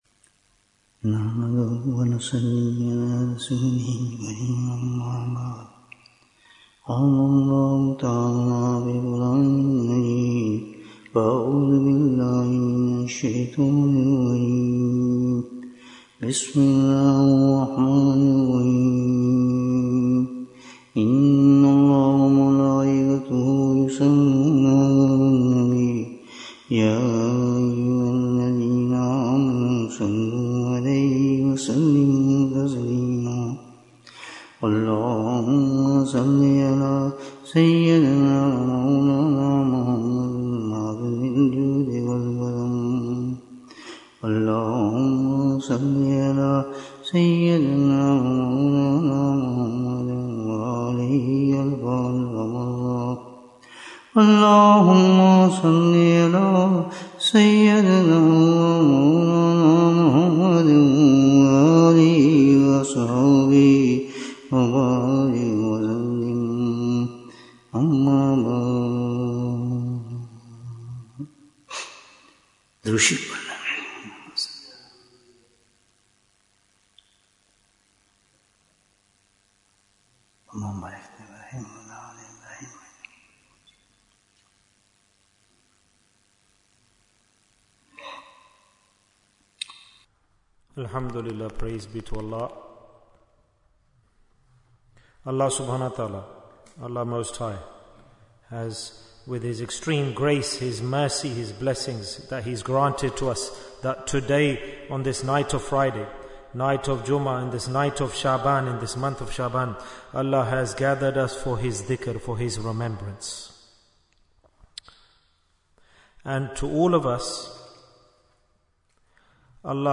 Why are the Two Months Before Ramadan Important? Bayan, 107 minutes6th February, 2025